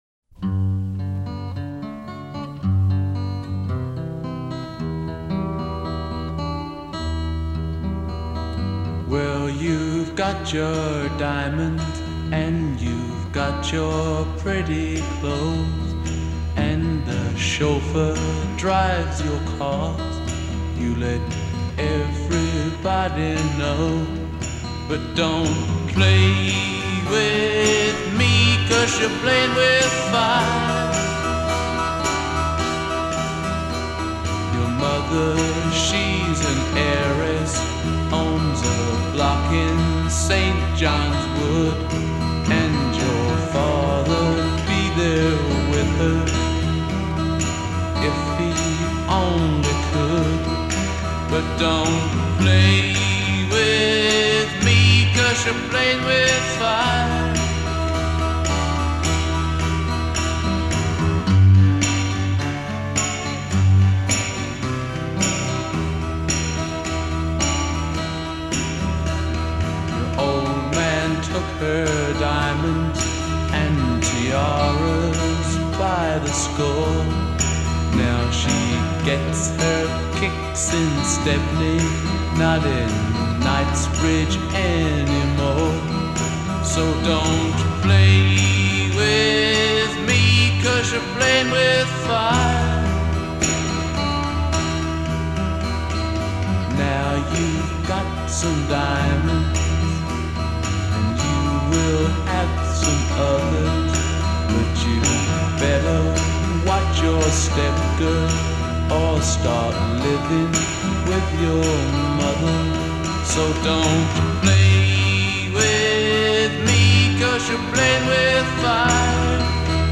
Standard Tuning - 4/4 Time